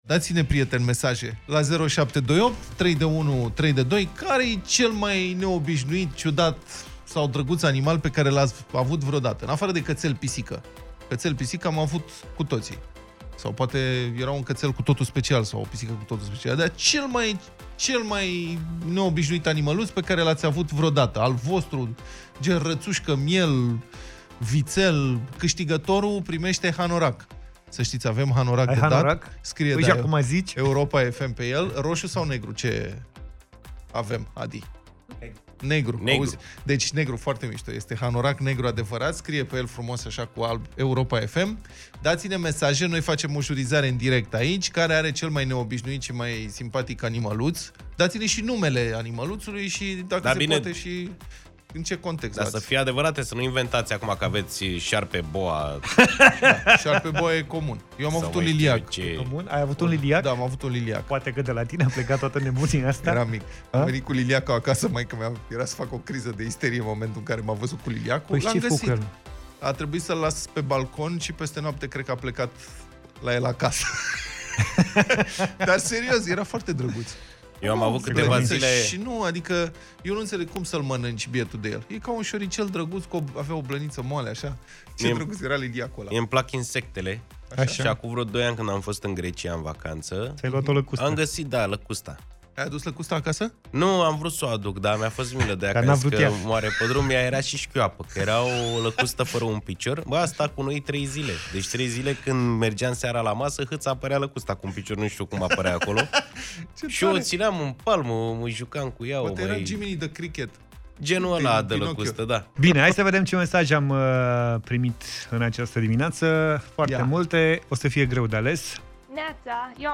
i-au provocat pe ascultătorii Deșteptarea să spună ce animal neobișnuit de companie au avut